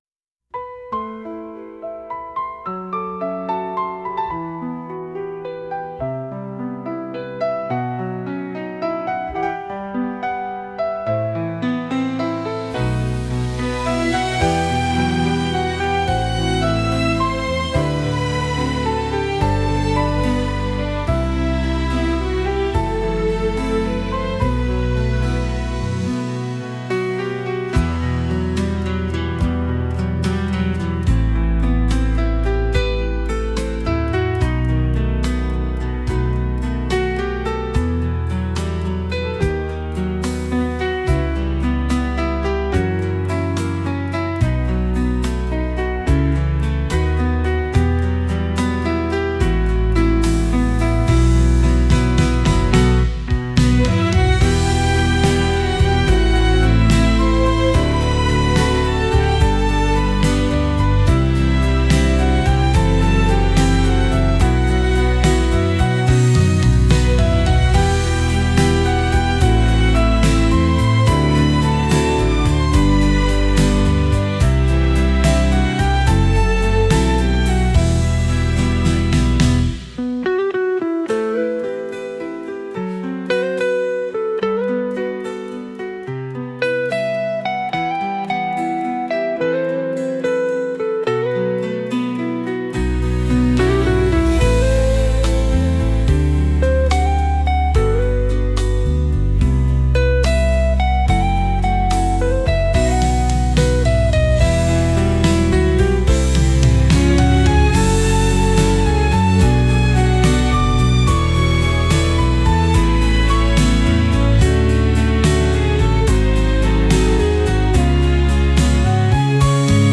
悪くないんだけど、何だか演歌風になってしまって💦mp3で配布します。